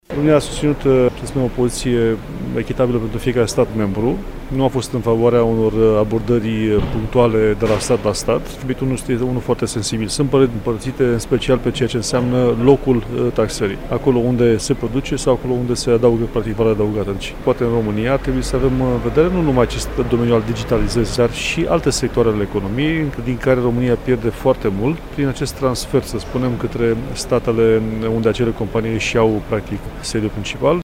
România a fost reprezentată de ministrul de Finanţe, Eugen Teodorovici şi a susţinut în cadrul discuţiilor o poziţie echitabilă pentru toate statele. Într-un interviu pentru Radio România Actualităţi, demnitarul a precizat că şi ţara noastră trebuie să beneficieze de acest sistem, pentru a evita pierderile din anumite domenii: